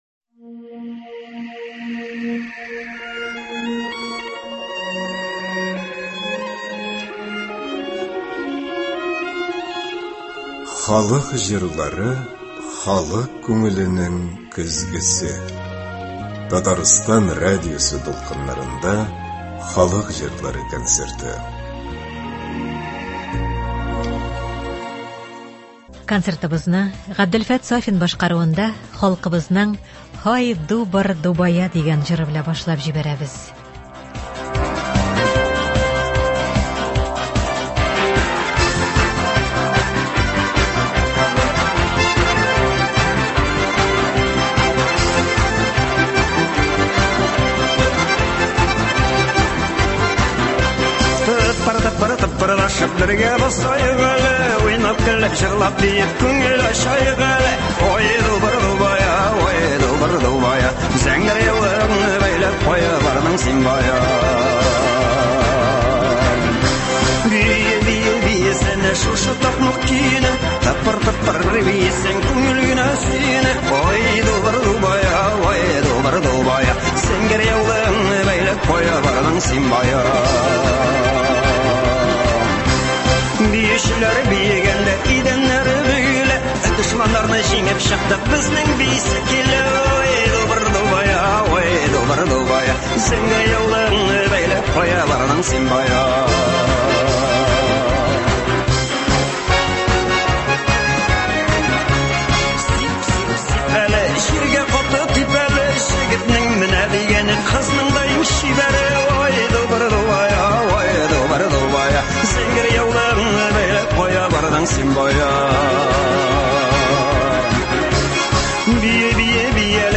Татар халык җырлары (06.05.23)